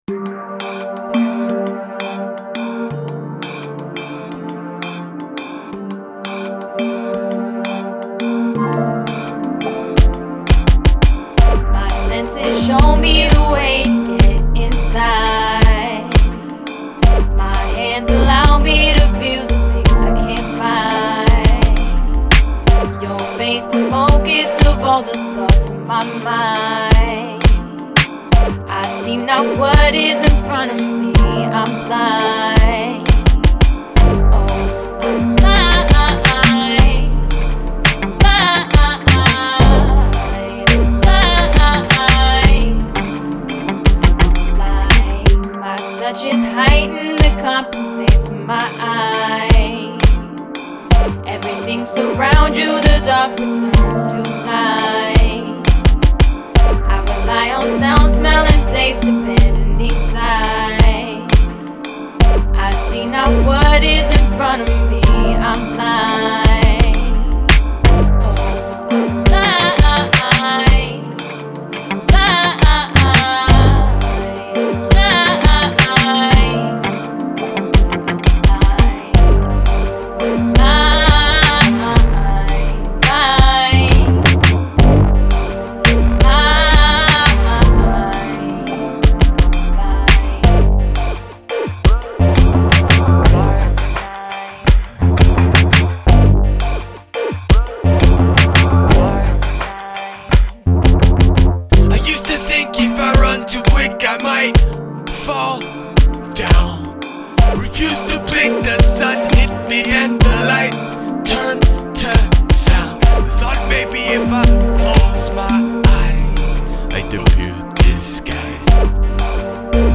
Recorded at Ground Zero Studios